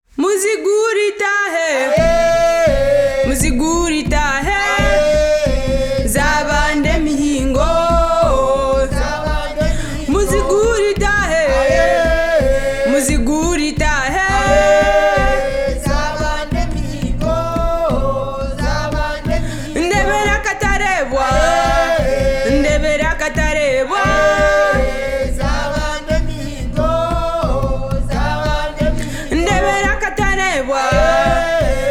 embuutu (big drum), namunjoloba (small drum)
ensaasi (shakers), ebinyege (rattles)